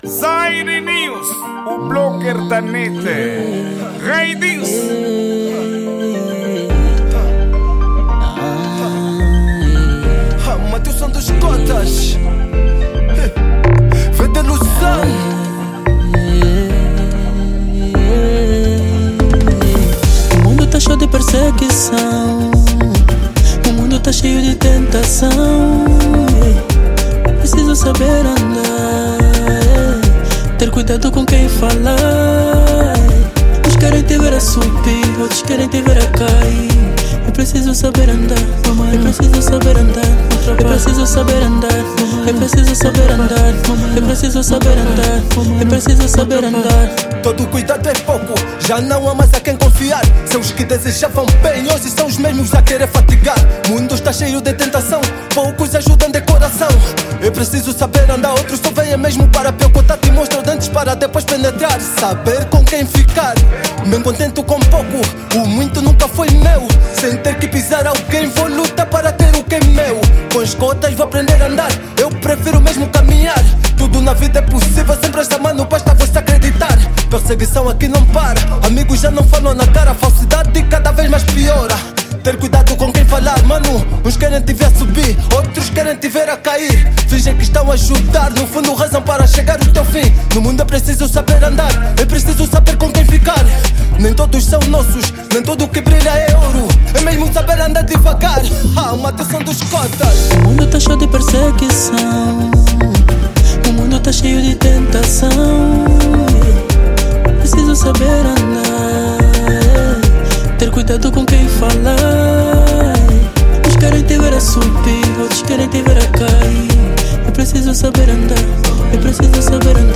Gênero:Kuduro